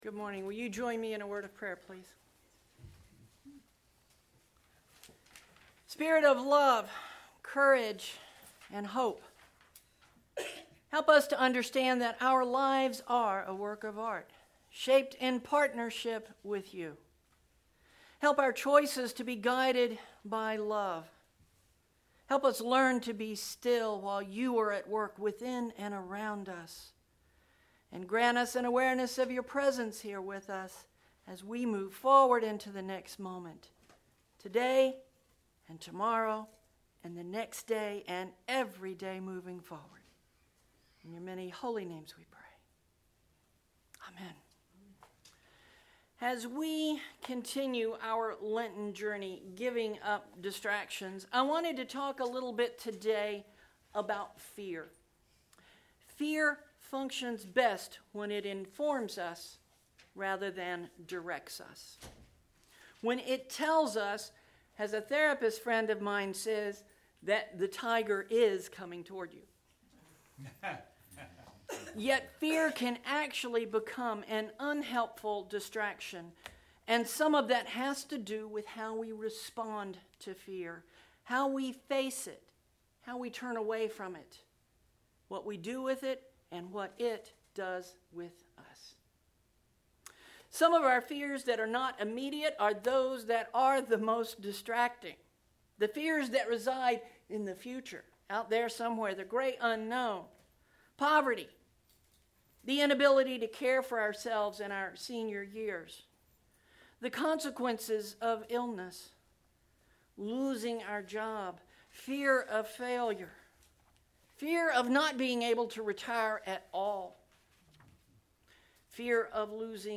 Click below to listen to the sermon from Sunday 03/17/19